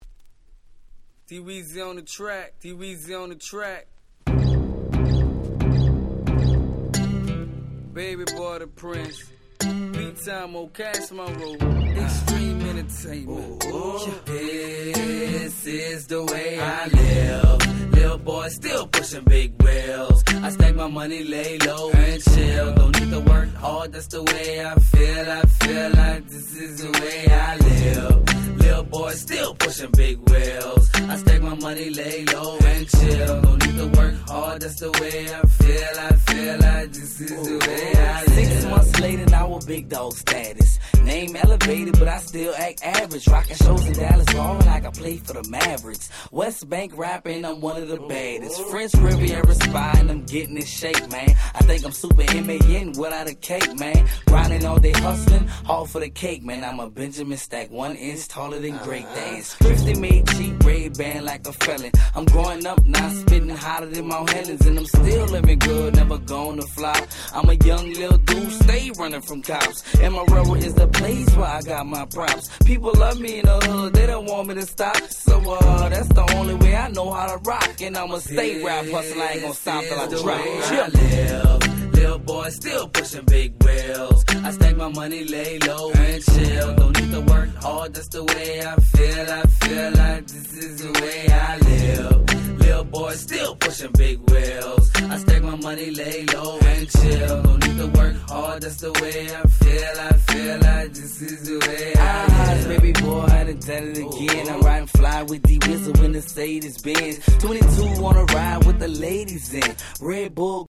06' Super Hit Southern Hip Hop !!